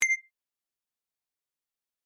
決定ボタン02 - 音アリー